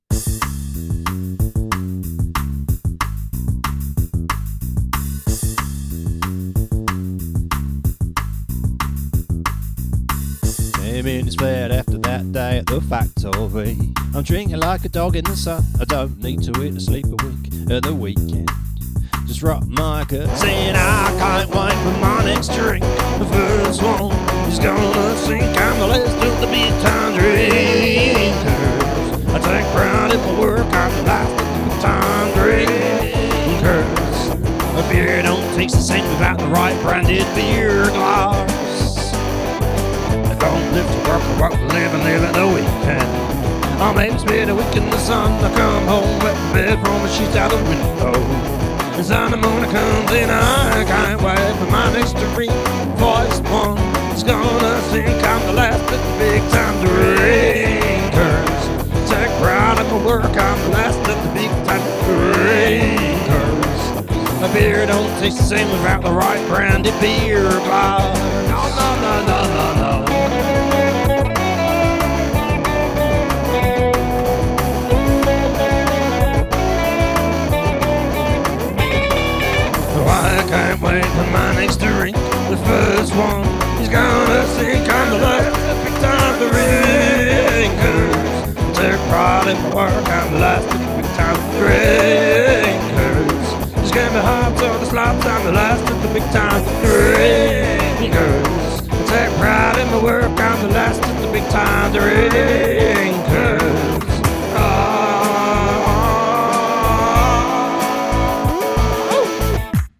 Salsa vibe